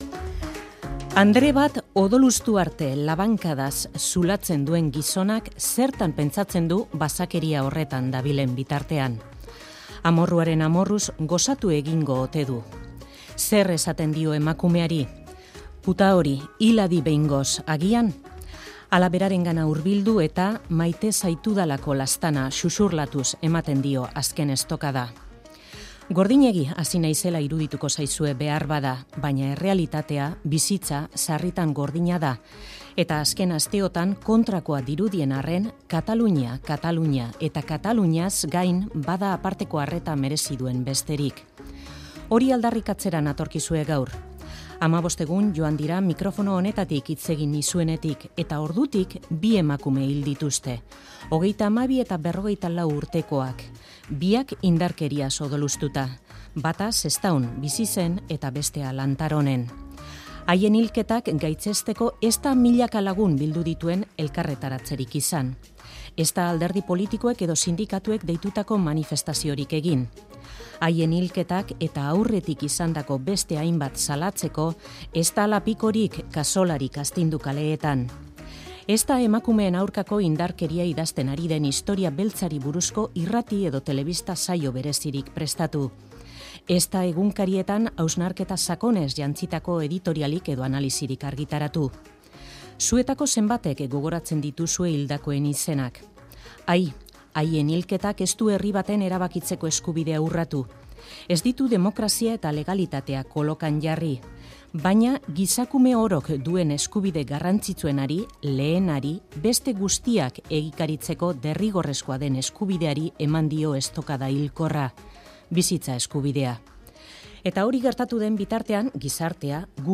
Euskadi Irratiko iritzi-jardunean